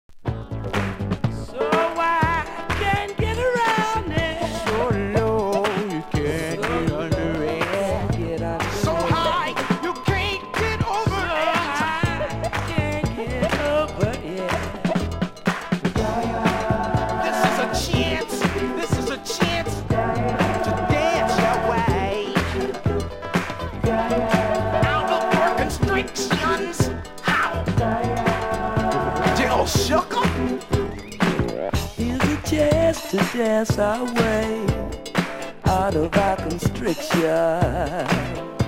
70年代後半はファンク寄りな音作りになって今度はディスコシーンに進出。
(税込￥1650)   FUNK